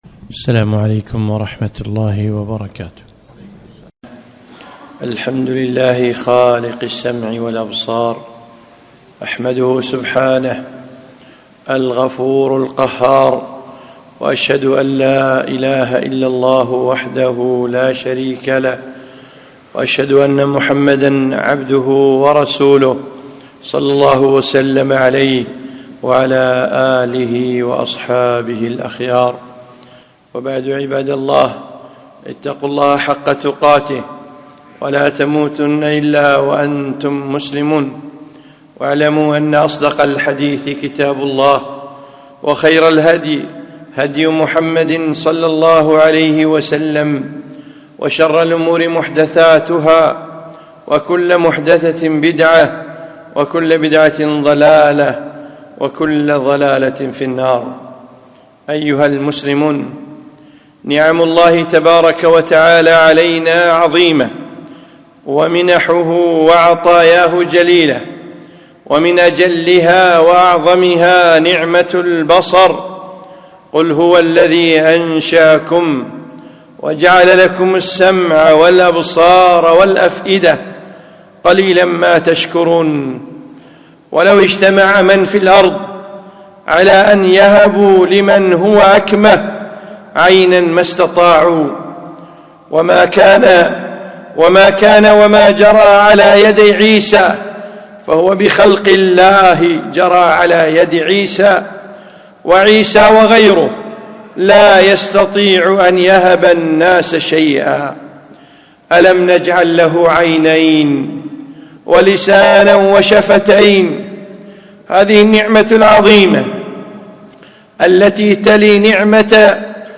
خطبة - احفظ بصرك